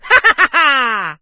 leon_kill_vo_02.ogg